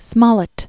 (smŏlĭt), Tobias George 1721-1771.